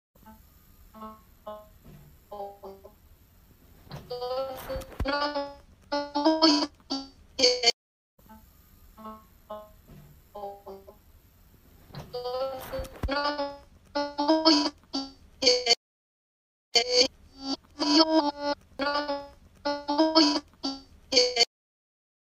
Звуки лагающего микрофона